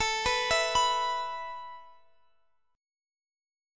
Listen to E5/A strummed